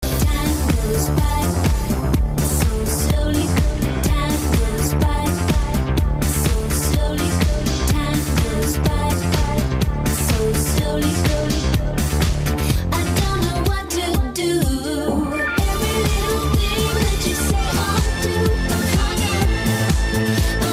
FM-тюнер
В целом приемлемое качество приёма в FM-режиме, всё-таки, не дотягивает до лучших результатов в моих условиях.
Качество звука заслуживает хорошей оценки (скачать